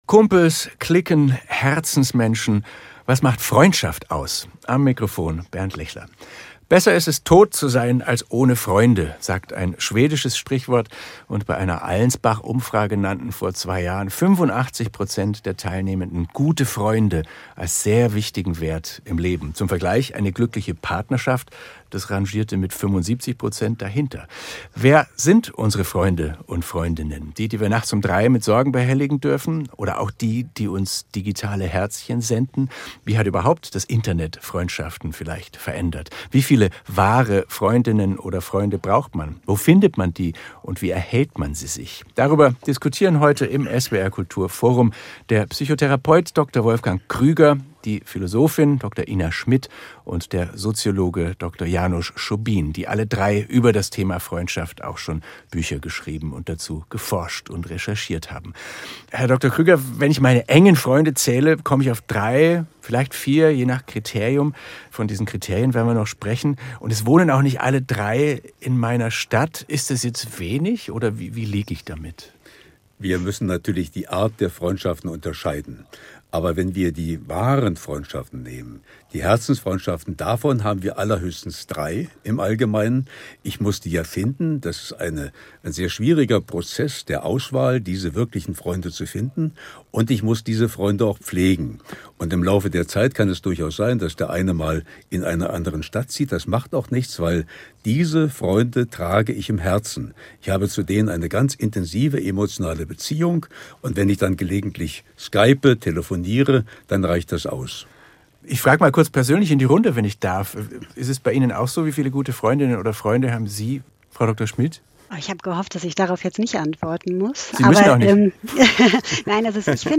Podcast SWR Kultur Forum – Diskussionen und Gespräche
Im SWR Kultur Forum diskutieren wir über Gott und die Welt, über Fußball und den Erdball. Unsere Gäste kommen aus Wissenschaft, Literatur und Kultur – und manchmal auch aus der Politik.